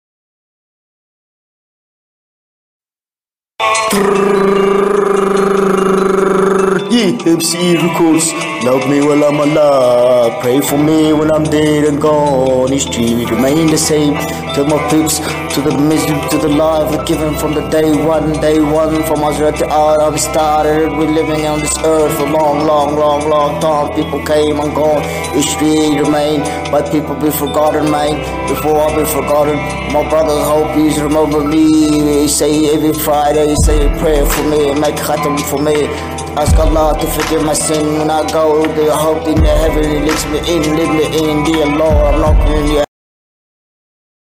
Zdravím, na pozadní téhle šílené rapové skladby je slyšet vyzvánění z nějakého telefonu, nevíte z jakého telefonu to je?